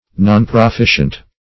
Search Result for " nonproficient" : The Collaborative International Dictionary of English v.0.48: nonproficient \non`pro*fi"cient\, a. Not proficient.
nonproficient.mp3